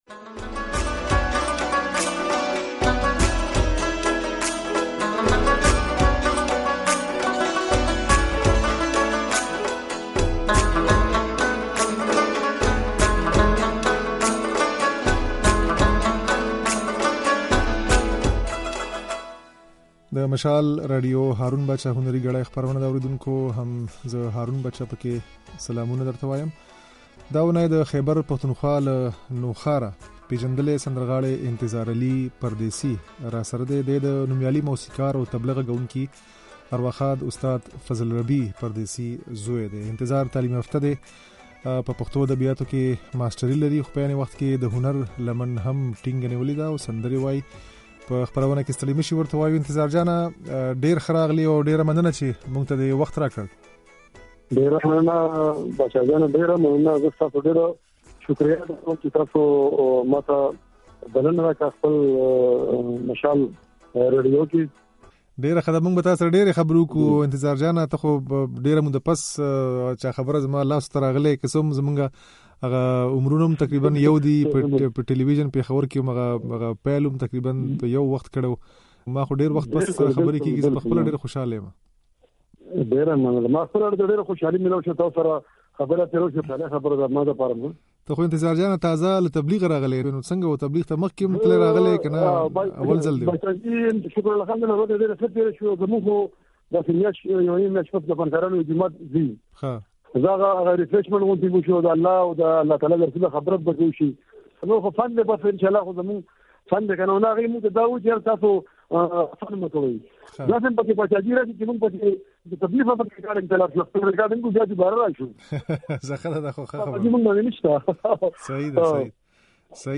د ده خبرې او څو سندرې يې د غږ په ځای کې اورېدای شئ.